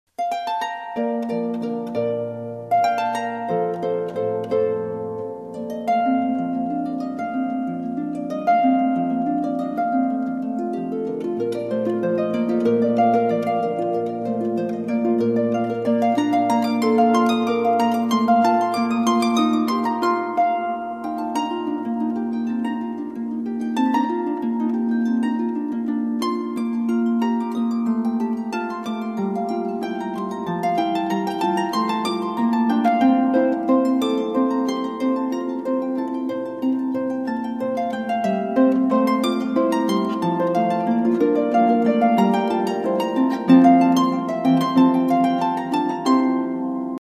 Wedding Harpist
harp arrangements